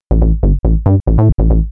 Techno / Bass / SNTHBASS139_TEKNO_140_A_SC2.wav
1 channel